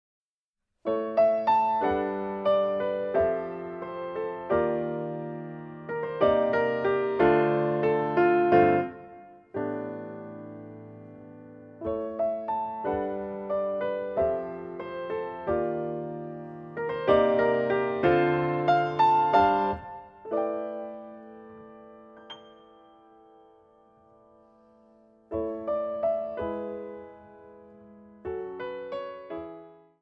ジャズ、ファンク、フュージョンをはじめ、さまざまなジャンルをエレガント、パワフル、そして団結力で圧倒させられる。